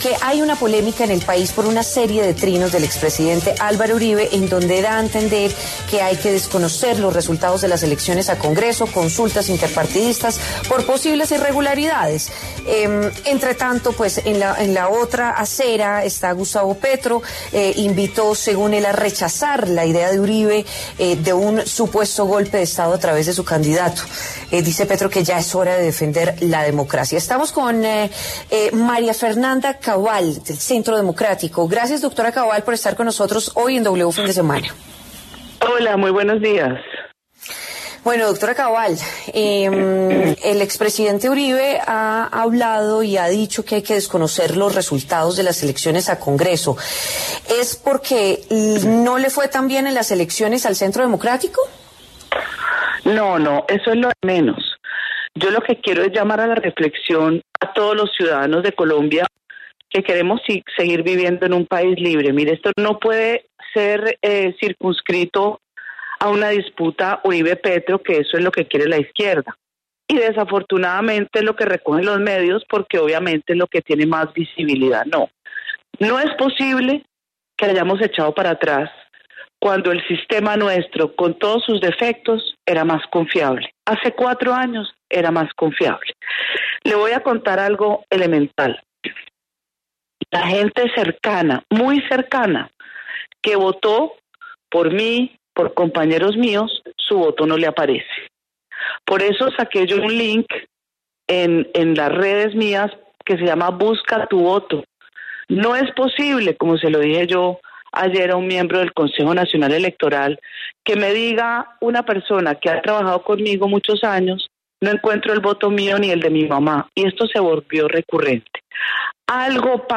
En diálogo con W Fin de Semana, la senadora María Fernanda, quien fue la mujer más votada para el Senado 2022-2026, se refirió a las peticiones del Centro Democrático.